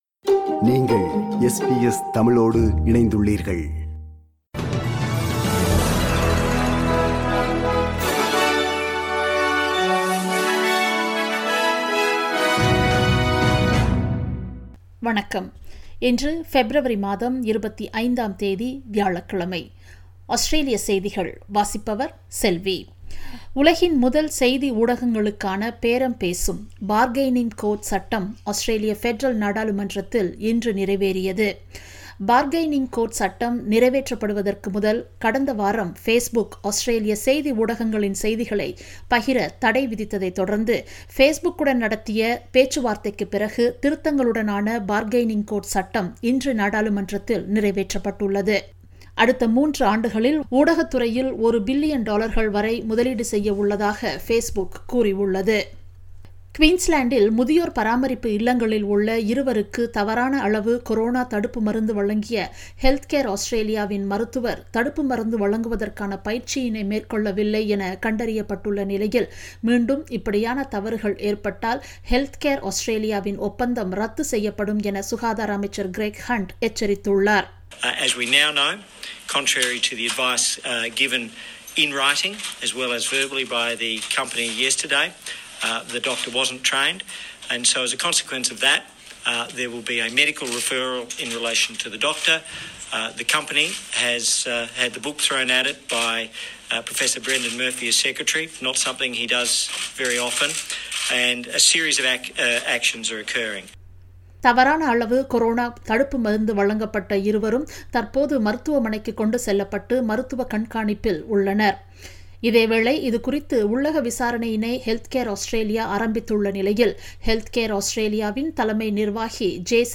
Australian news bulletin for Thursday 25 February 2021.